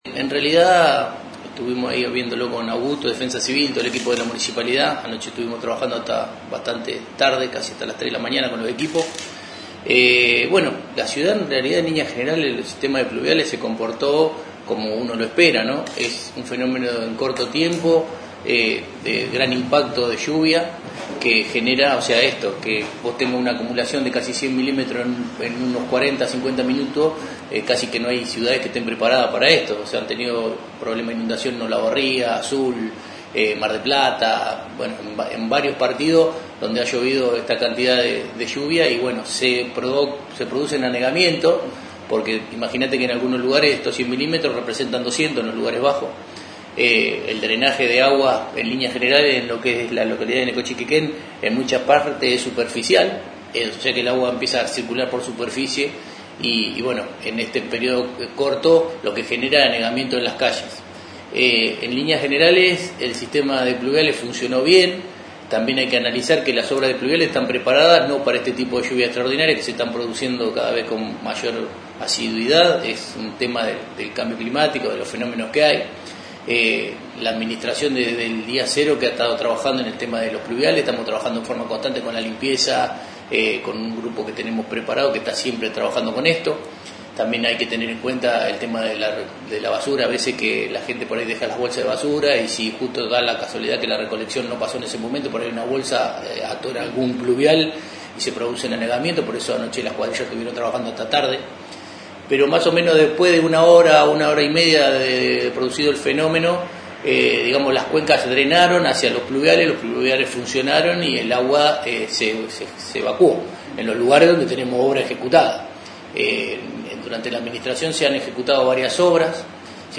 El secretario de Planeamiento, Obras y Servicios Públicos, Adrián Furno, explicó que se trató de un evento climático de gran intensidad y corta duración, con cerca de 100 milímetros caídos en apenas 50 minutos.